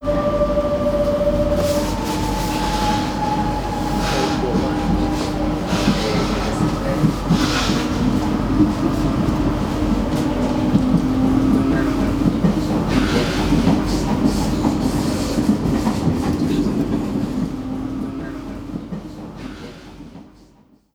Ambiente interior de un vagón del metro
ruido
subterráneo
Sonidos: Gente
Sonidos: Transportes